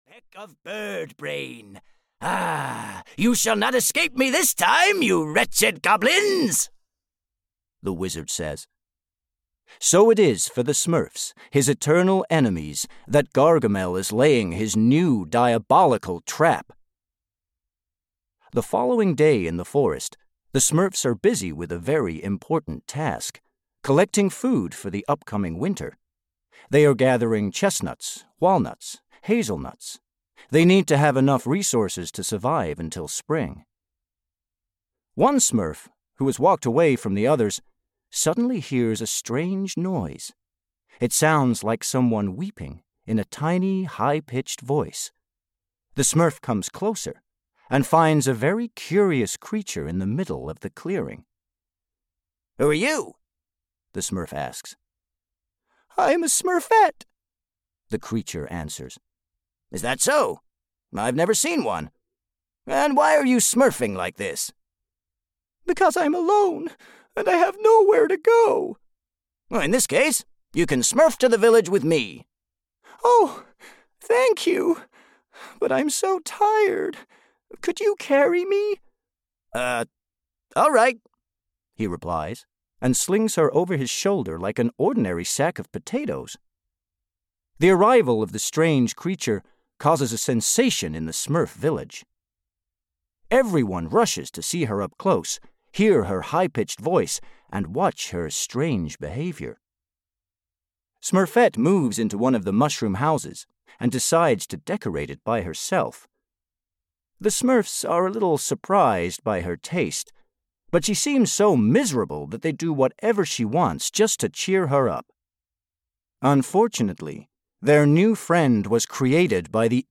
Ukázka z knihy
smurfs-storytime-collection-1-en-audiokniha